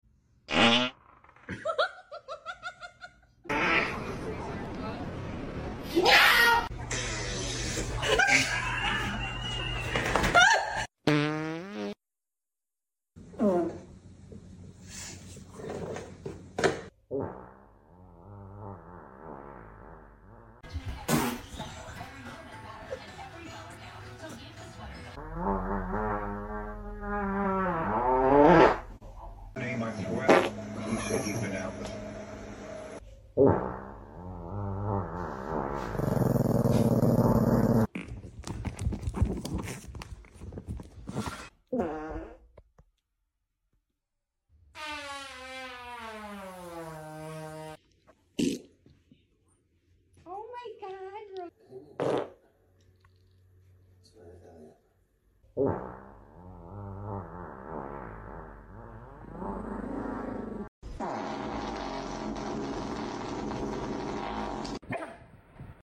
Dog's reaction to farting noises#US#dog sound effects free download